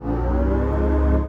heatShieldsBoost.wav